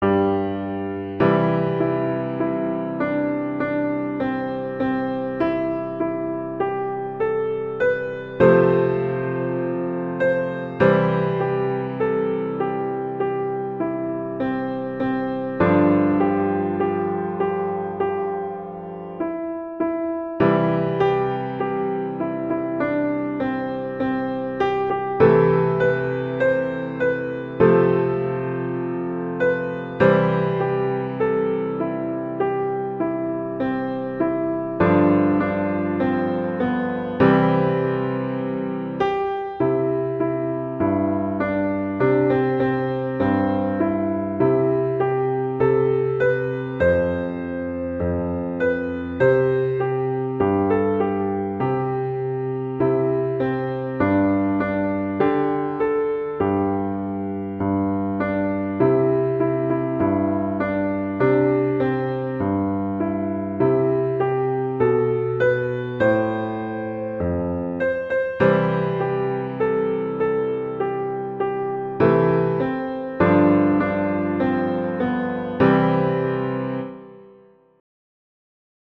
Instrumentation: piano solo
arrangements for piano solo
traditional, irish, children